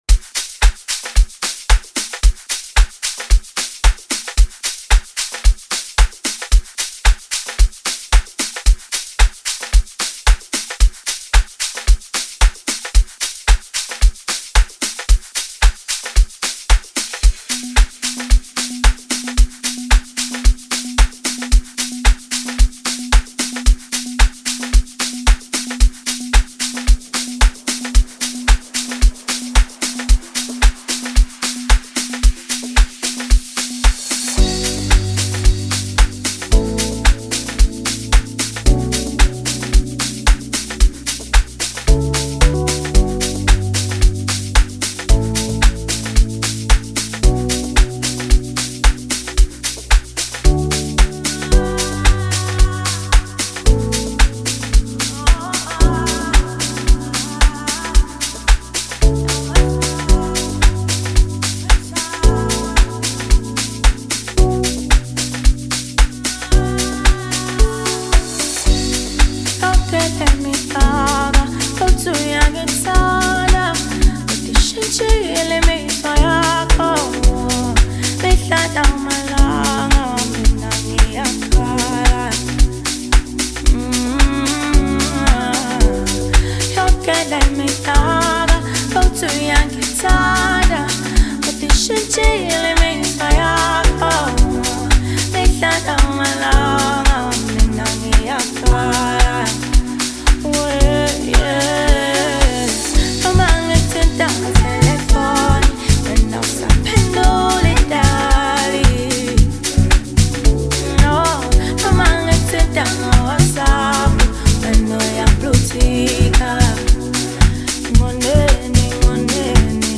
The talented South African singer-songwriter
soulful vocals